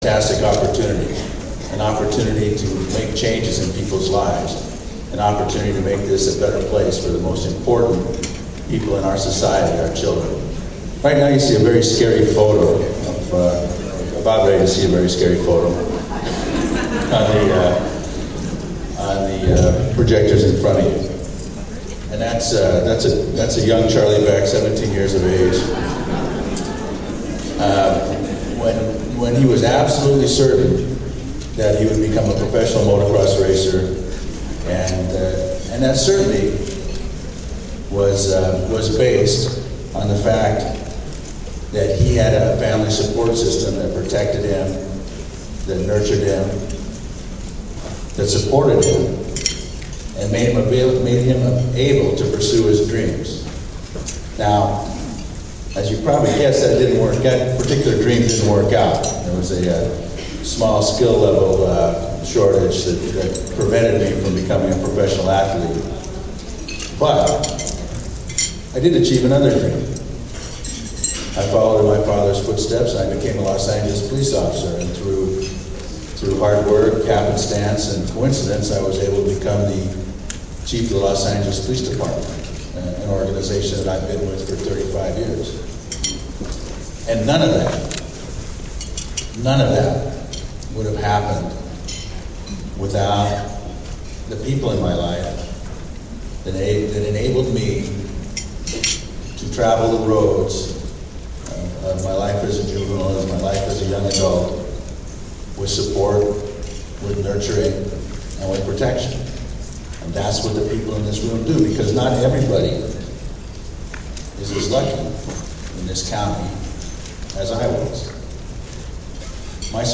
October 28, 2010- Chief Charlie Beck attended the Los Angeles County Inter-Agency Council on Child Abuse ICAN pic and Neglect (ICAN) conference held at the Bonaventure Hotel in Downtown Los Angeles. The goal of this year’s conference was to examine the “Nexus” between child abuse and domestic violence.
ican-conference.mp3